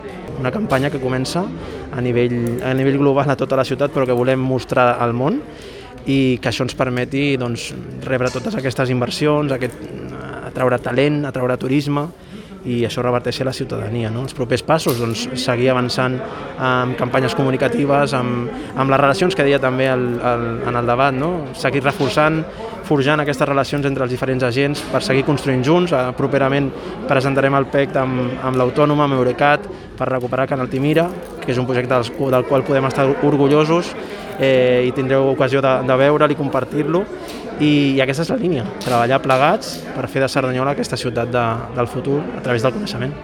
Declaracions de Carlos Cordón: